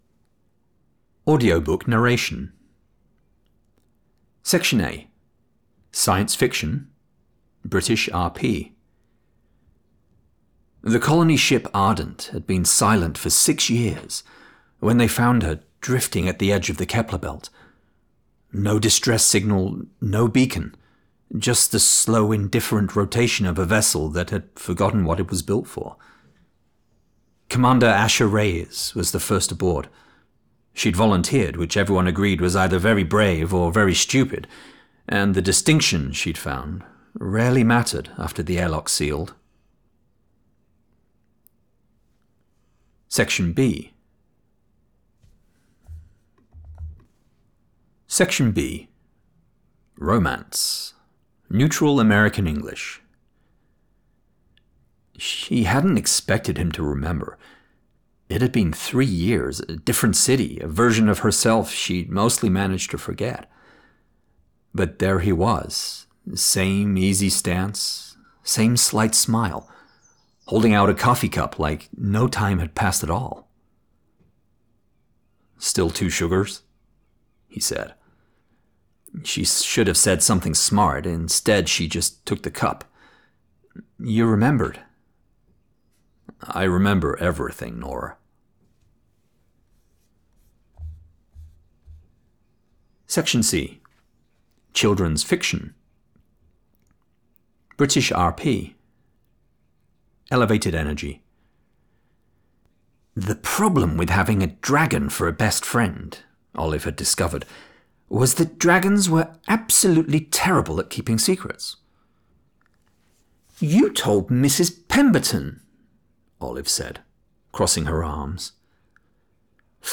Audiobook Narration
Middle Aged
DEMO 3 — Audiobook Narration.mp3